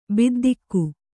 ♪ biddikku